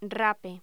Locución: Rape
voz
Sonidos: Voz humana